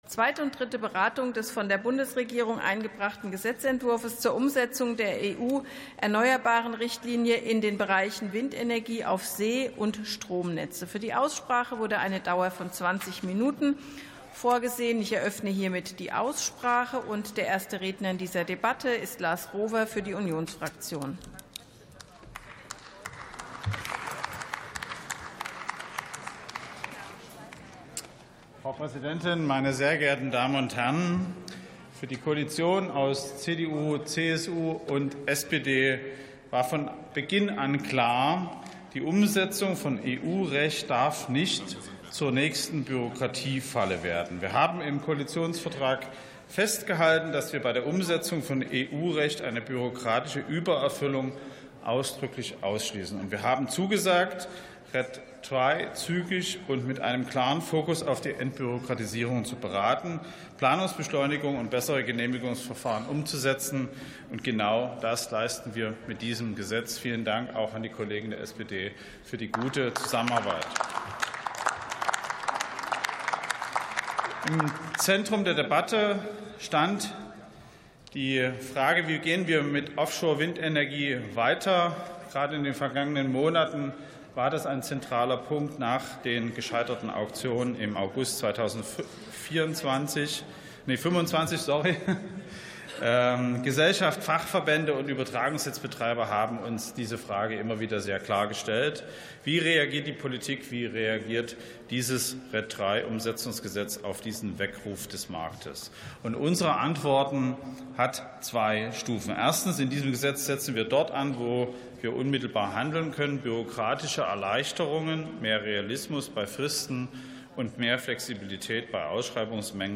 47. Sitzung vom 04.12.2025. TOP 19: EU-Erneuerbaren-Richtlinie ~ Plenarsitzungen - Audio Podcasts Podcast